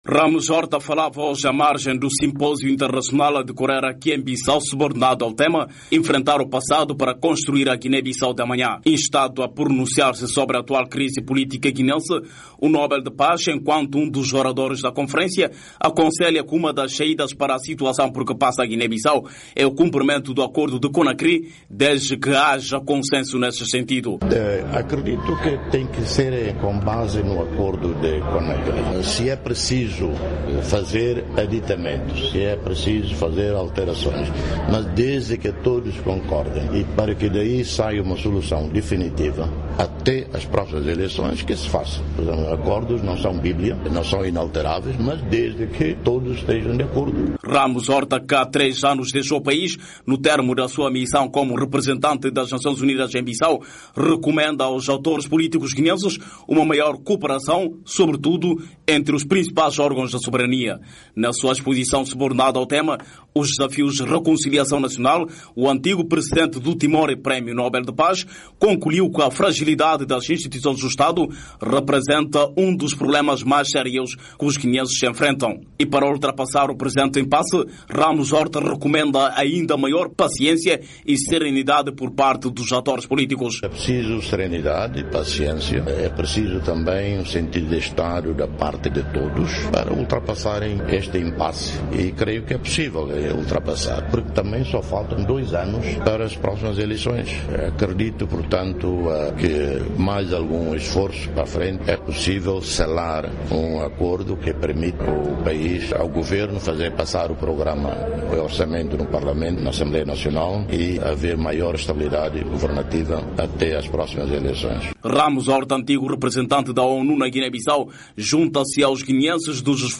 Ao falar nesta quinta-feira, 9, à margem do Simpósio Internacional sobre Reconciliação, a decorrer aqui em Bissau, defendeu um consenso entre as partes em conflito.